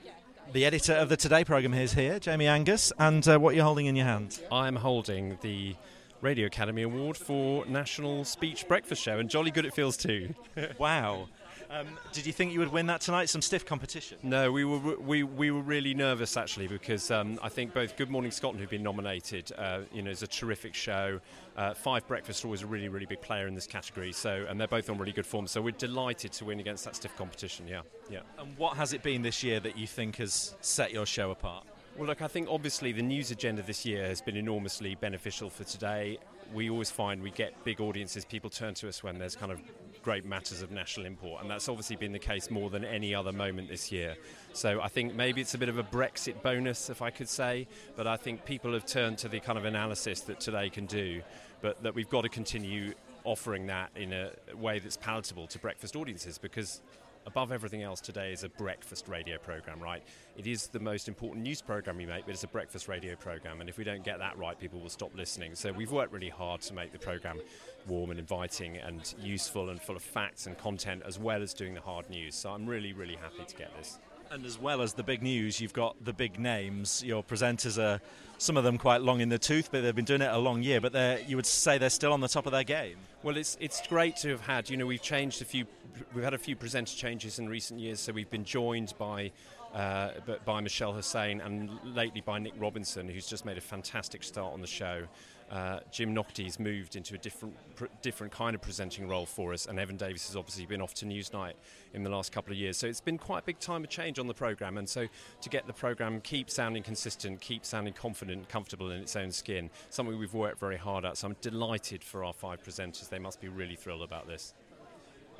Audio and Radio Industry Awards